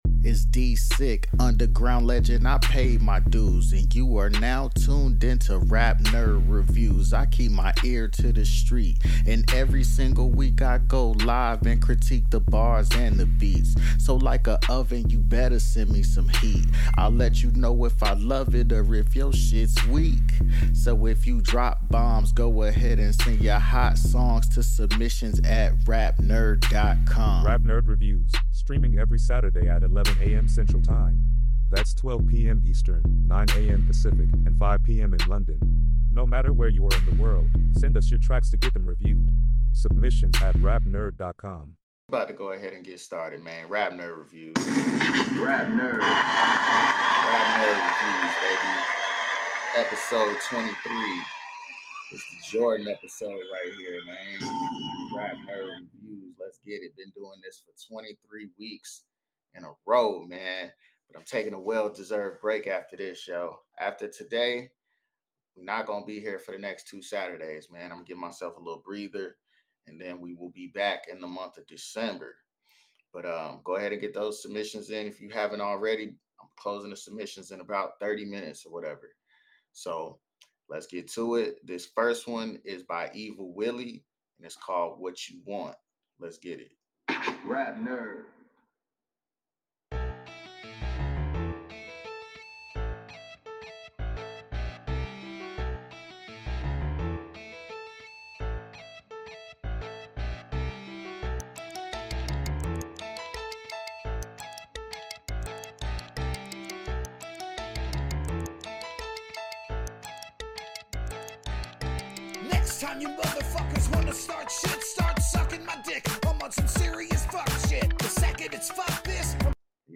If you missed the live stream, here’s your chance to cat…